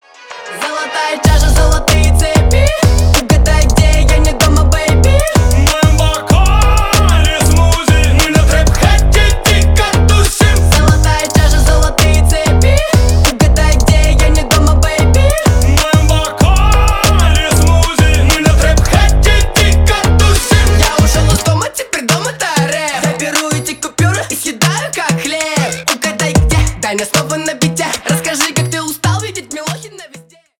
веселые
поп